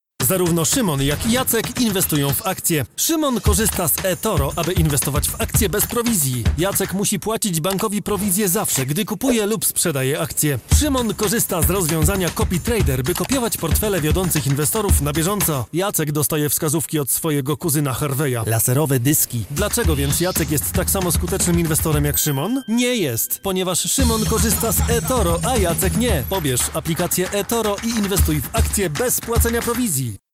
Male 30-50 lat
Demo lektorskie
Spot reklamowy